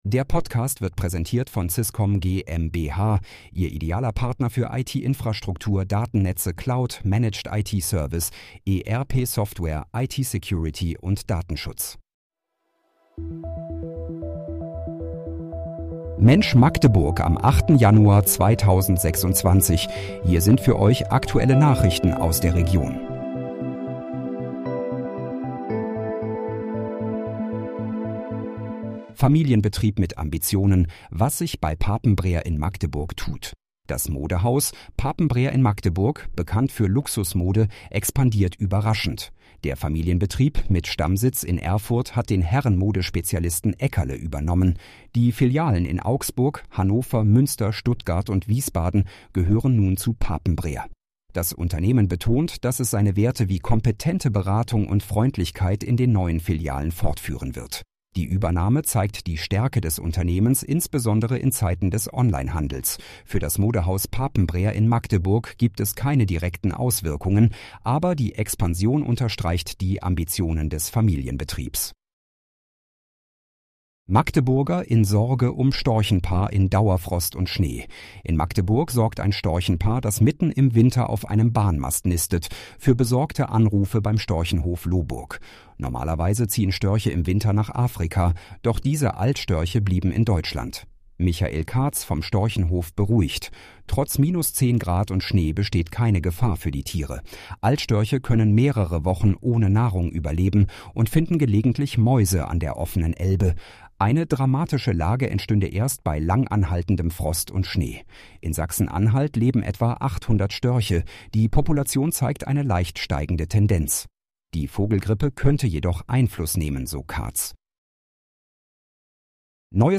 Mensch, Magdeburg: Aktuelle Nachrichten vom 08.01.2026, erstellt mit KI-Unterstützung